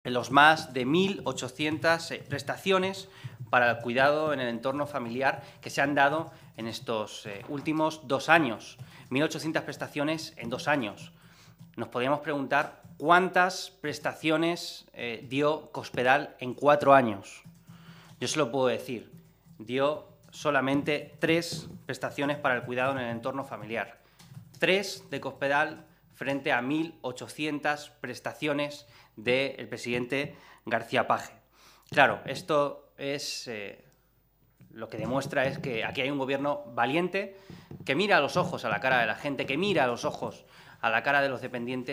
Cortes de audio de la rueda de prensa